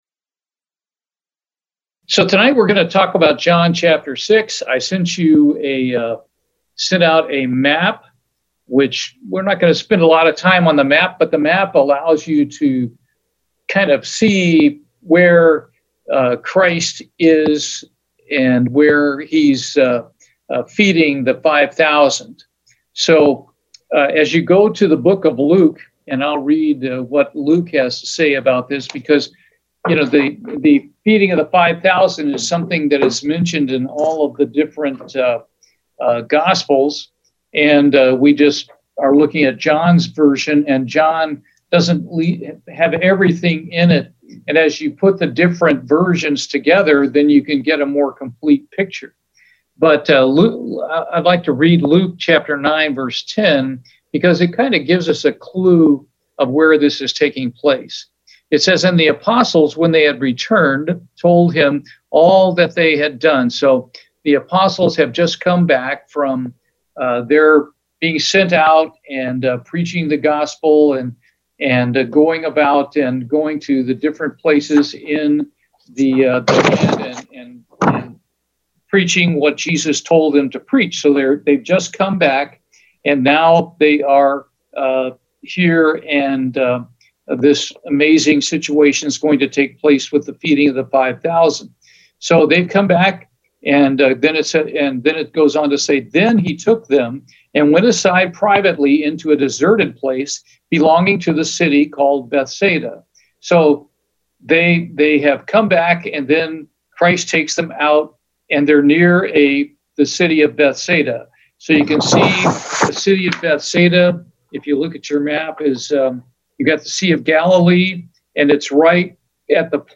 Video Bible Studies